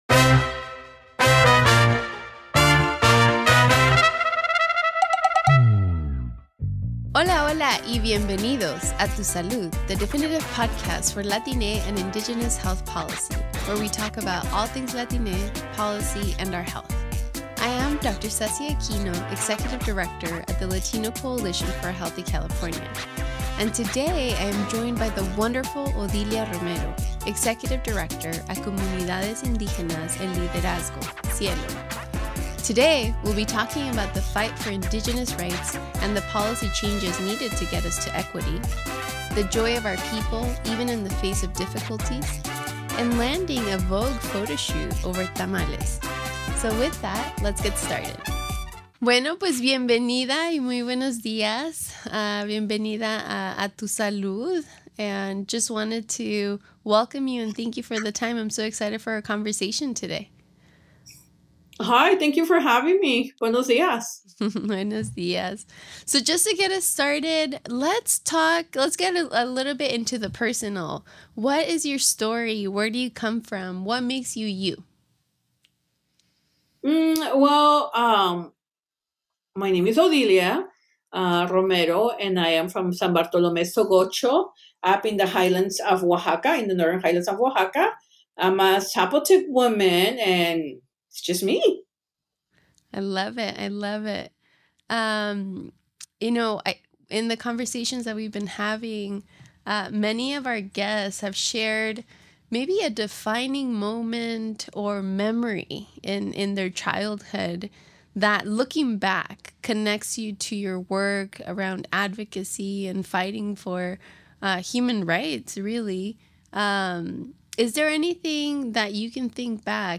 Join this engaging conversation between two trailblazing women as they explore the intersection of Indigenous and Latine health justice.